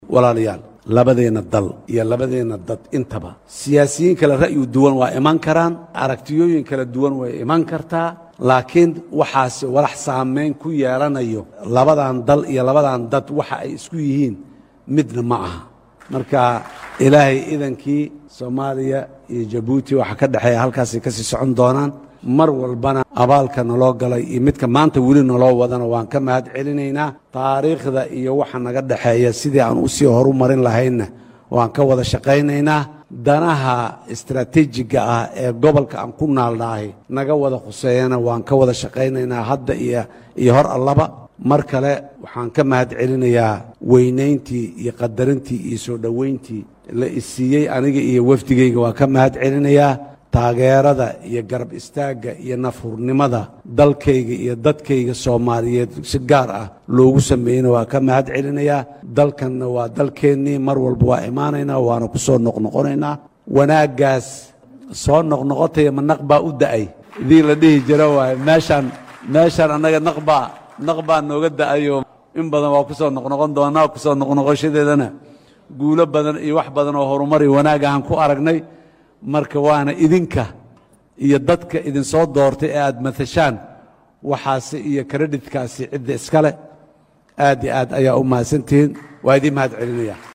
DHAGEYSO:Madaxweynaha Soomaaliya oo maanta khudbad u jeediyay baarlamaanka Jabuuti